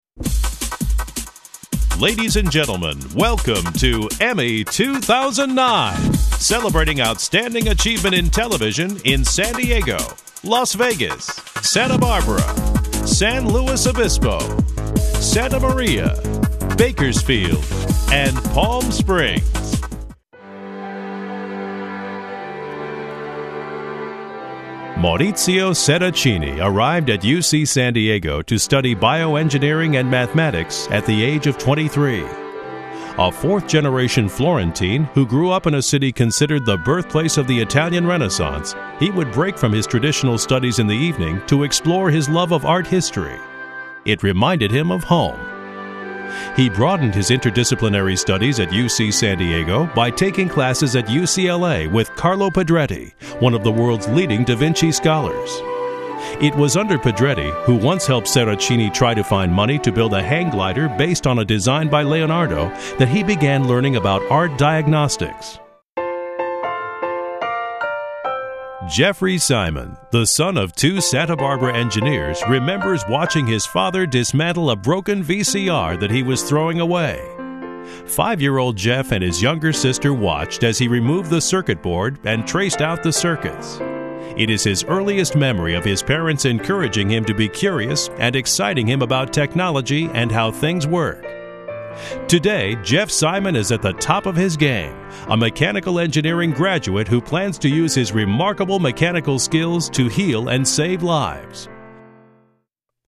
AWARD SHOW DEMO